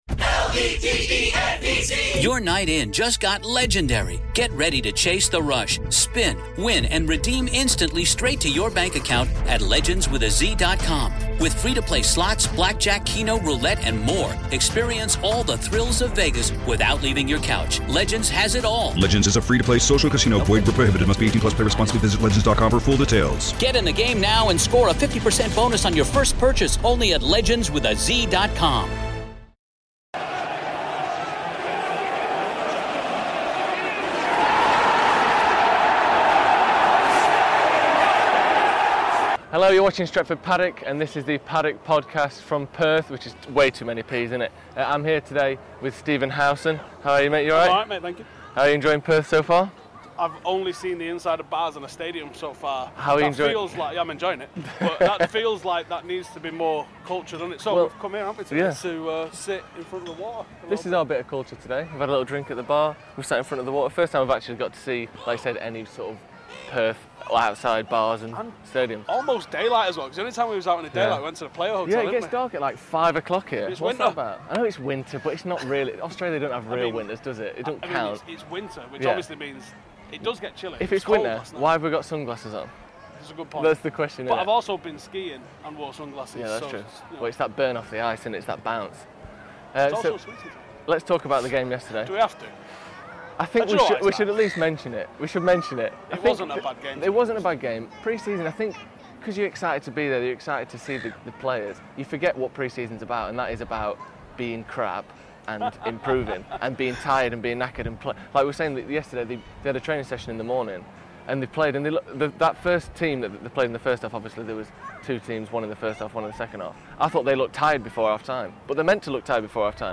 On this episode of the Paddock Podcast, we are live from Perth in Australia!